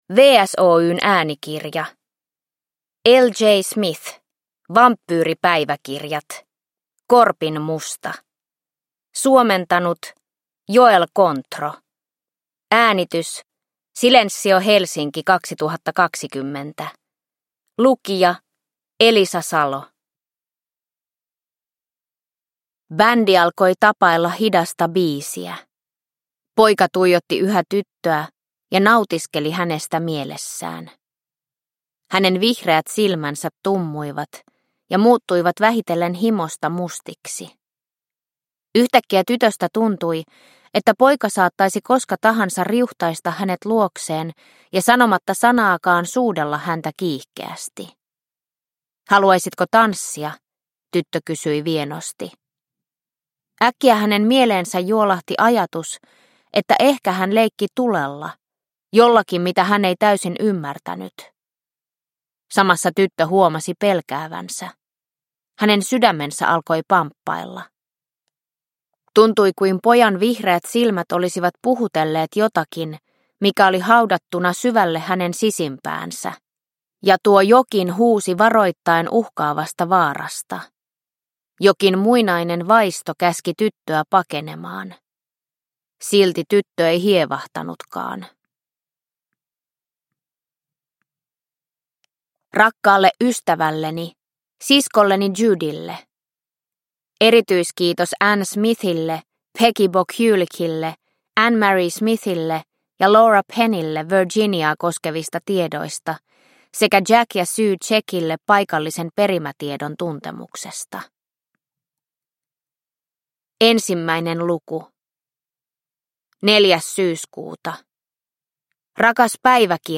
Korpinmusta – Ljudbok – Laddas ner